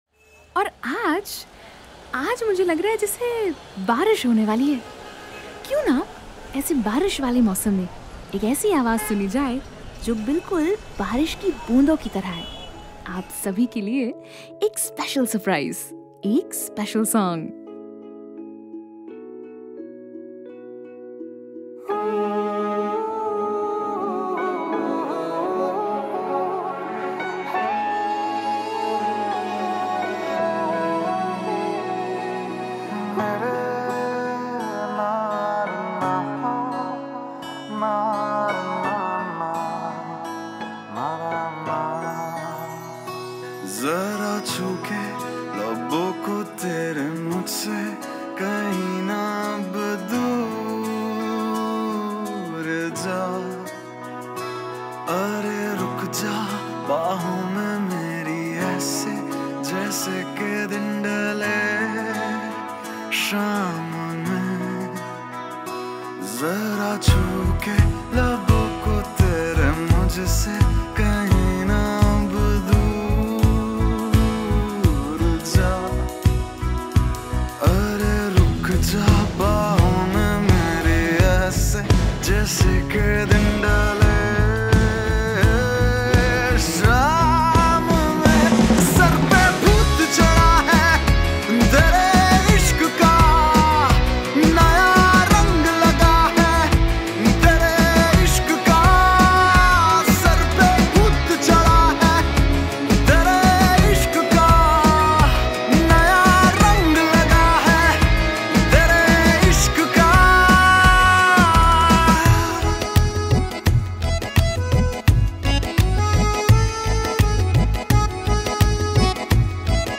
Single Pop Songs